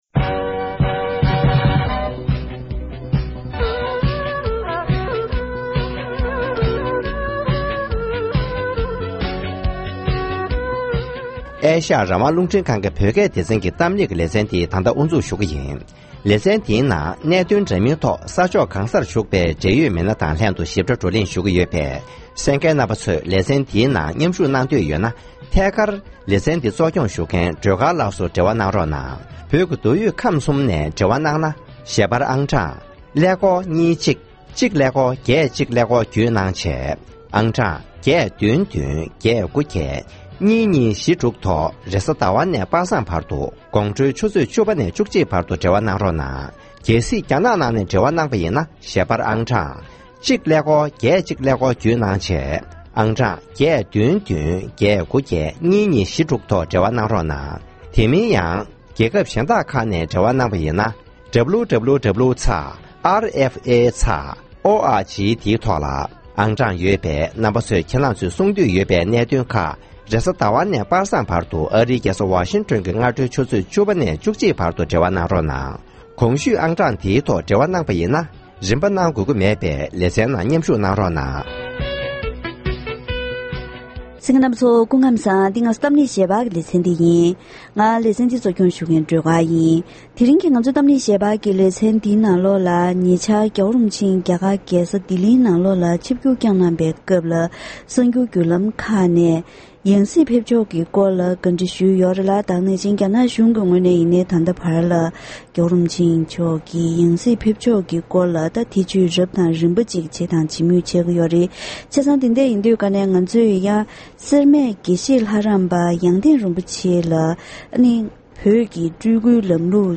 ༄༅། །དེ་རིང་གི་གཏམ་གླེང་ཞལ་པར་ལེ་ཚན་ནང་བོད་ཀྱི་སྤྲུལ་སྐུའི་ལམ་ལུགས་ཐོག་མར་བྱུང་སྟངས་དང་། སྤྲུལ་སྐུའི་ལམ་ལུགས་འདིས་བོད་ཀྱི་སྤྱི་ཚོགས་ལ་ཤུགས་རྐྱེན་གང་འདྲ་བྱུང་མིན། ད་ཆ་རྒྱ་ནག་གཞུང་གིས་སྤྲུལ་སྐུའི་ལམ་ལུགས་འདིར་སྒྱུར་བ་གཏོང་བཞིན་ཡོད་པའི་ཐོག་ནས་༧གོང་ས་མཆོག་གི་ཡང་སྲིད་ཕེབས་ཕྱོགས་ལ་སོགས་པའི་སྐོར་ལ་གླེང་མོལ་ཞུས་པ་ཞིག་གསན་རོགས་གནང་།